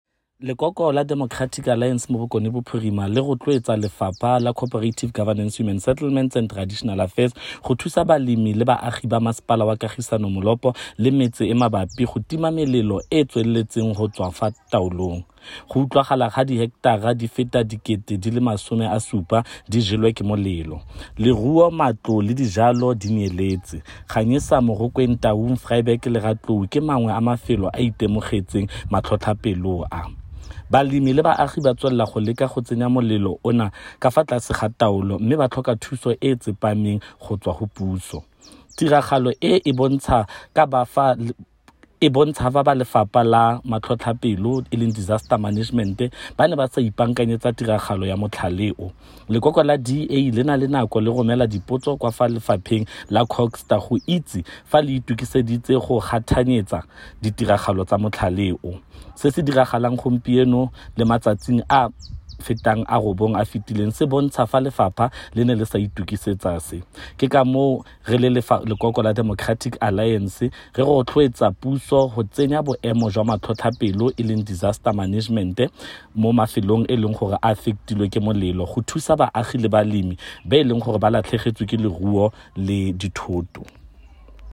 Note to Editors: Please find the attached soundbite in
Setswana by Freddy Sonakile MPL.